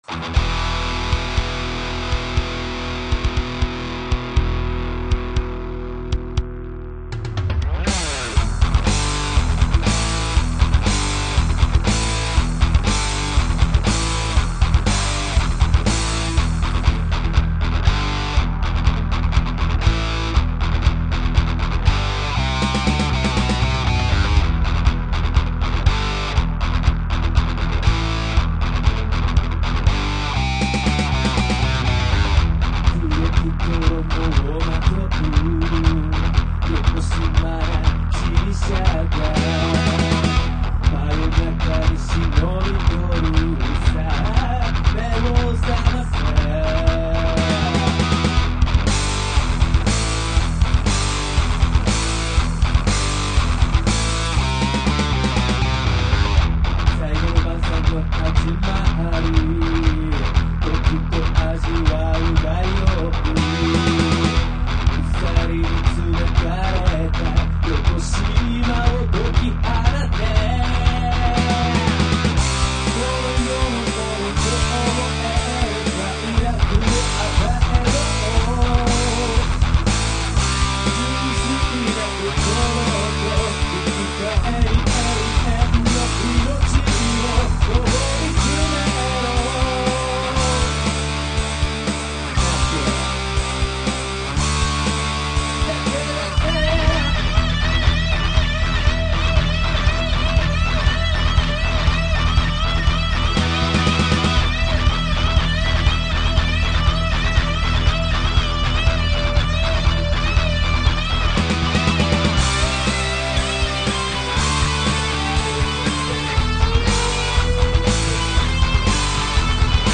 今回、初のDTMでやってみましたが、